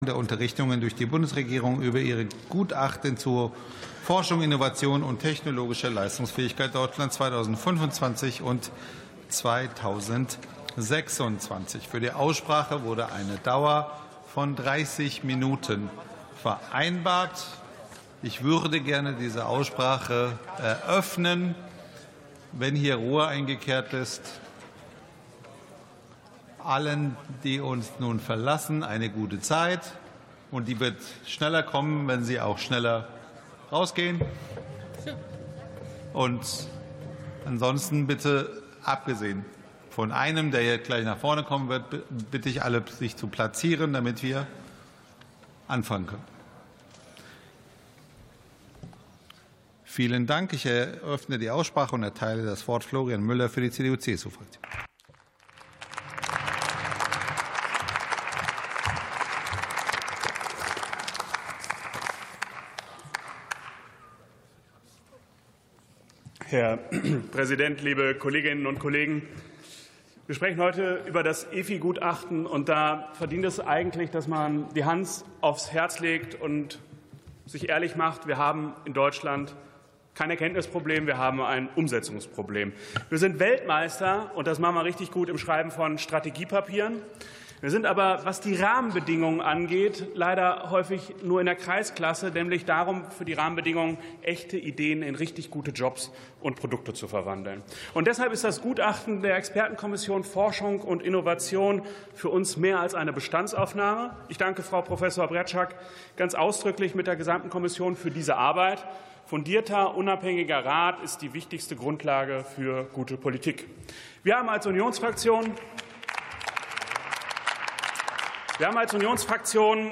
63. Sitzung vom 06.03.2026. TOP 23: Gutachten zu Forschung und Innovation 2026 ~ Plenarsitzungen - Audio Podcasts Podcast